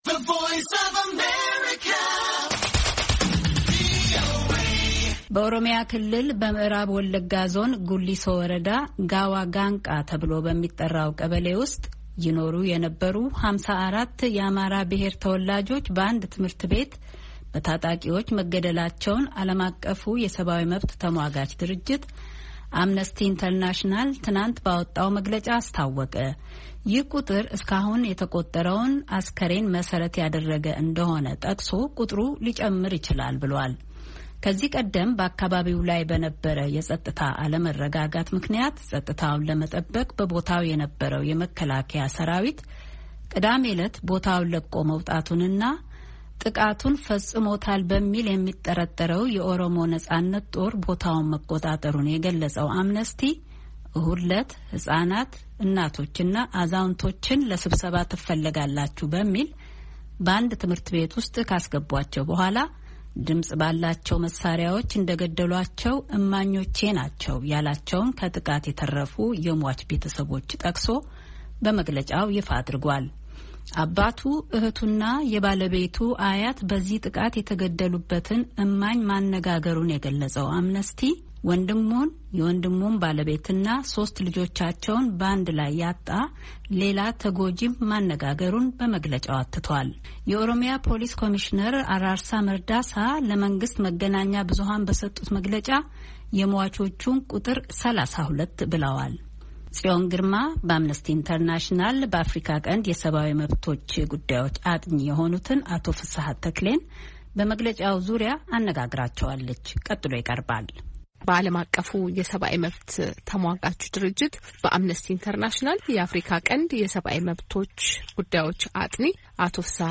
ቃለ ምልልስ ከአምነስቲ ኢንተርናሽናል አጥኒ ጋር